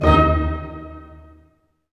Index of /90_sSampleCDs/Roland L-CD702/VOL-1/HIT_Dynamic Orch/HIT_Staccato Oct